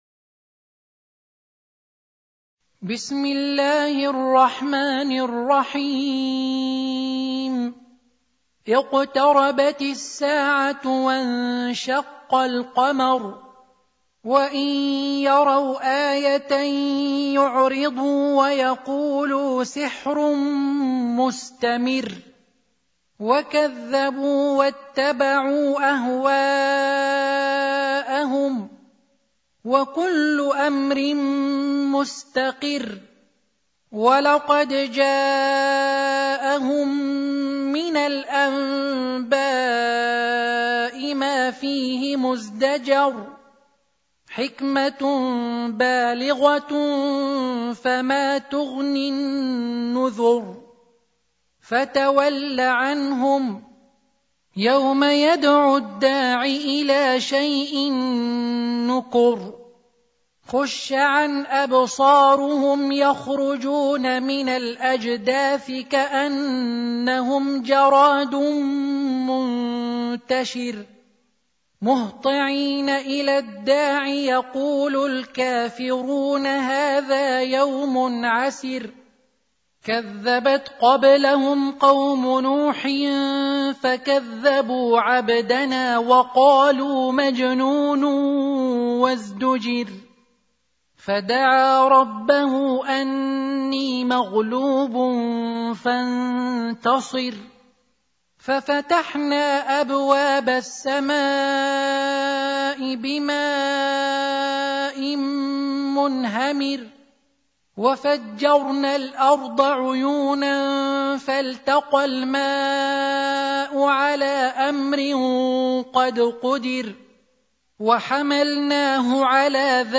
54. Surah Al-Qamar سورة القمر Audio Quran Tarteel Recitation
Surah Sequence تتابع السورة Download Surah حمّل السورة Reciting Murattalah Audio for 54. Surah Al-Qamar سورة القمر N.B *Surah Includes Al-Basmalah Reciters Sequents تتابع التلاوات Reciters Repeats تكرار التلاوات